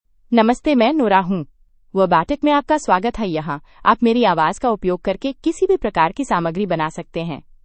NoraFemale Hindi AI voice
Nora is a female AI voice for Hindi (India).
Listen to Nora's female Hindi voice.
Female
Nora delivers clear pronunciation with authentic India Hindi intonation, making your content sound professionally produced.